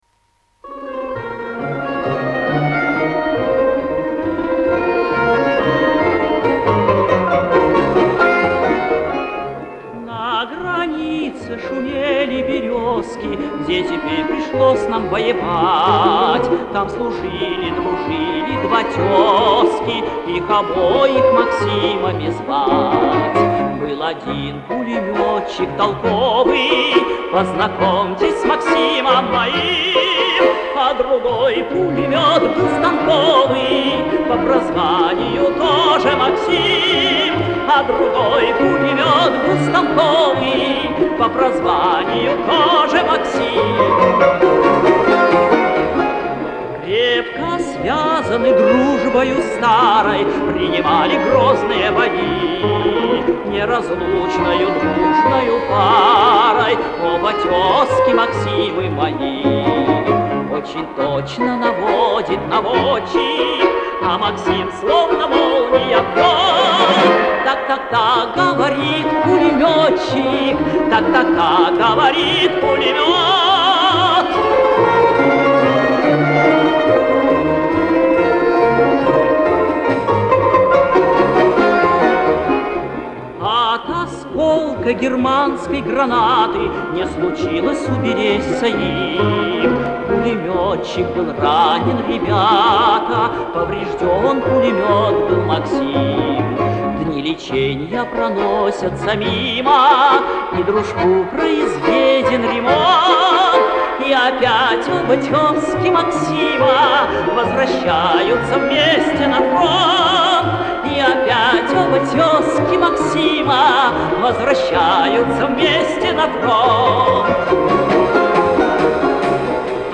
Позднее и ИМХО менее удачное исполнение.